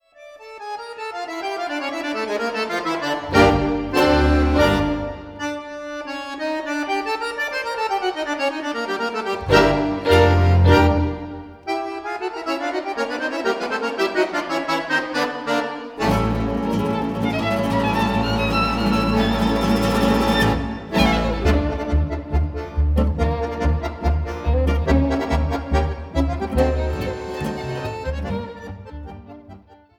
Violine
Bajan
Gitarre
Kontrabass